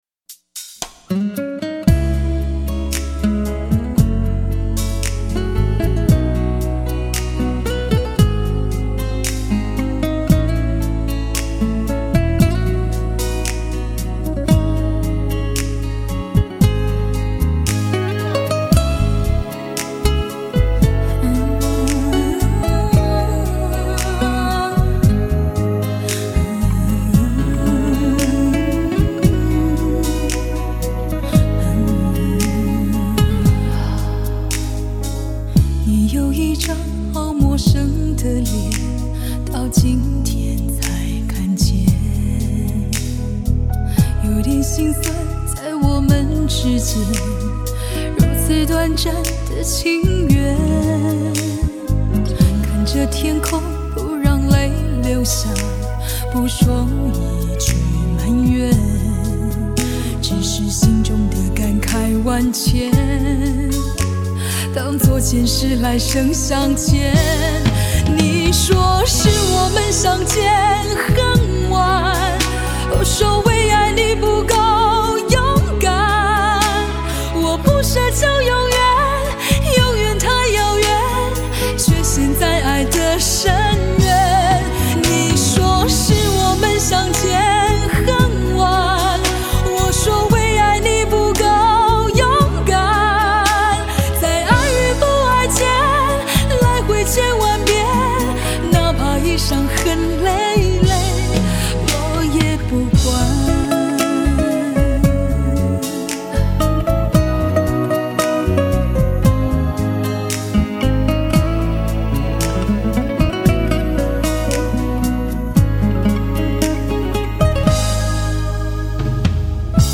精选华语流行乐坛最经典怀旧的发烧金曲，经历岁月磨砺的动容之声，怀旧音乐之旅